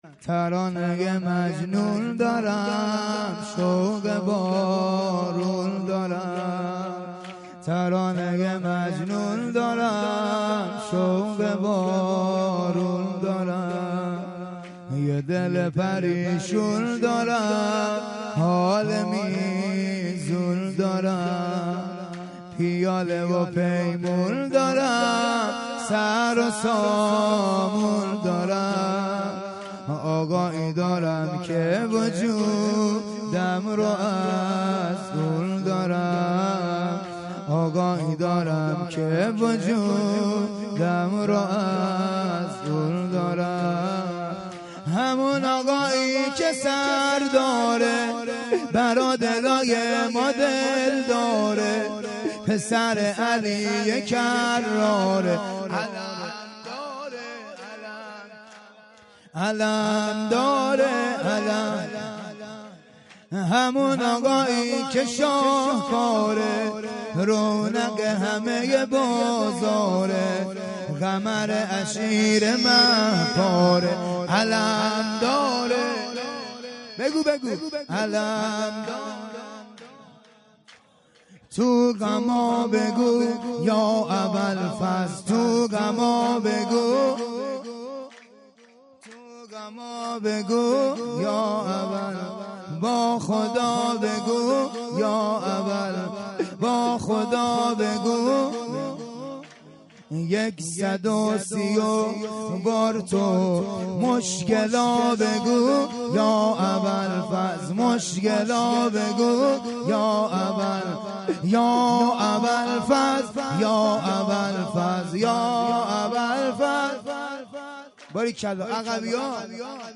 فایل های صوتی شب جشن میلاد امام زمان 22/اردیبهشت